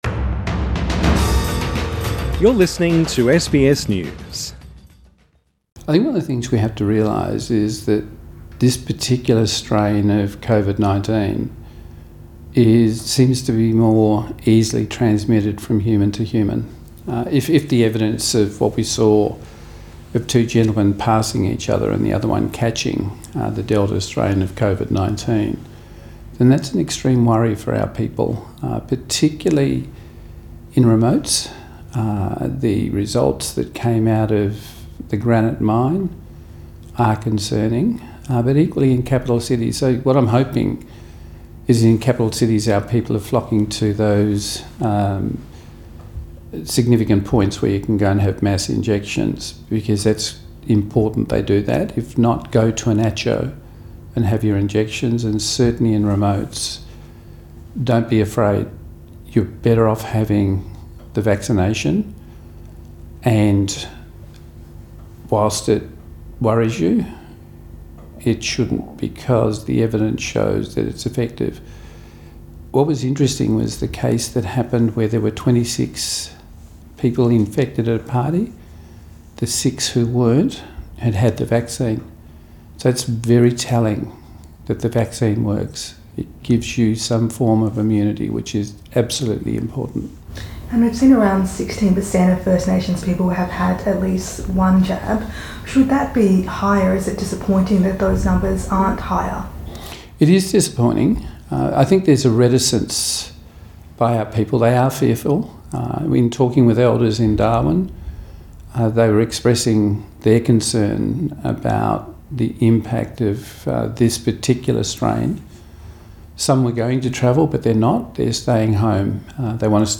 Minister for Indigenous Australians Ken Wyatt Source: SBS